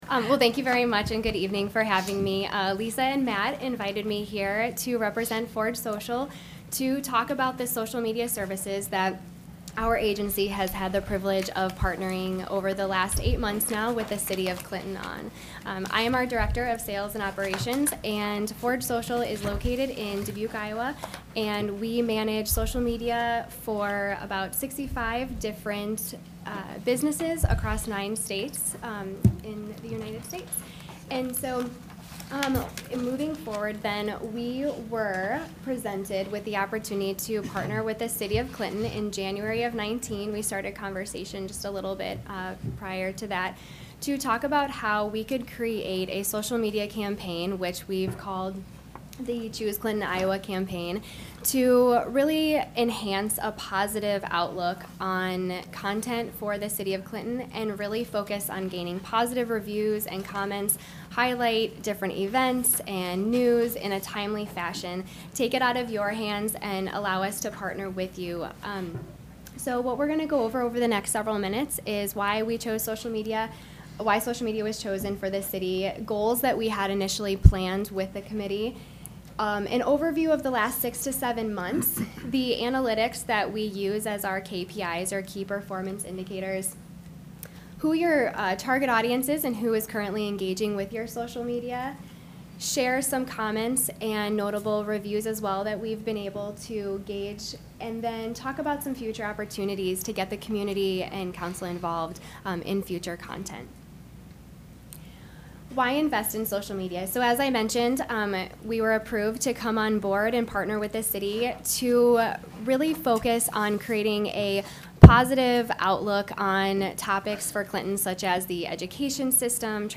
Choose Clinton Iowa Social Media Campaign (links included to various social media campaigns) – KROS Radio